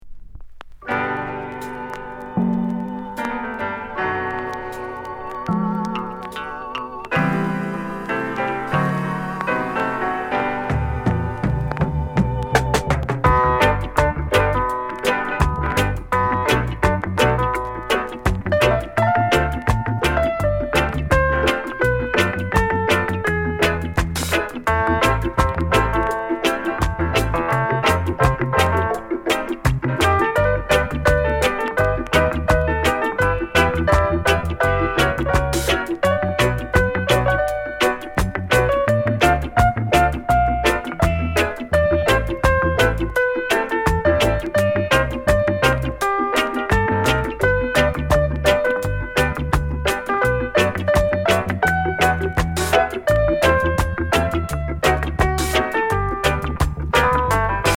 RARE SOUL COVER